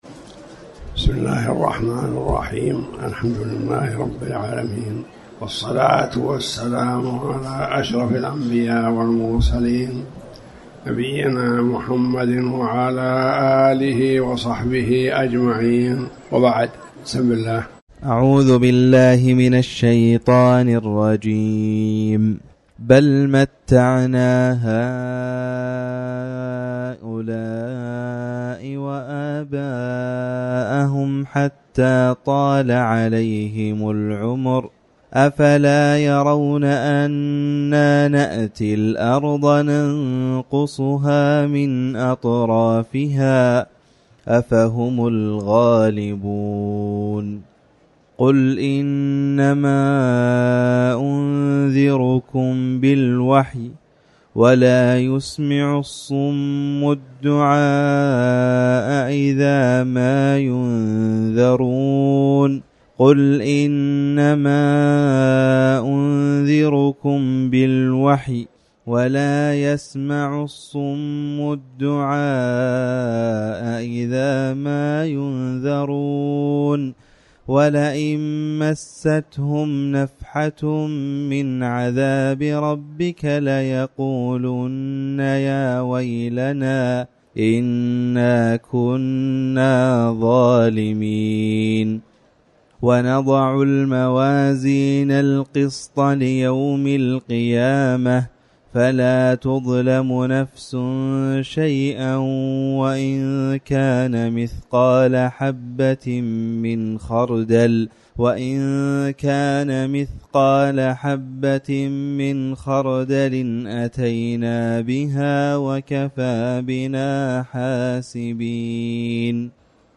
تاريخ النشر ٢٢ جمادى الآخرة ١٤٤٠ هـ المكان: المسجد الحرام الشيخ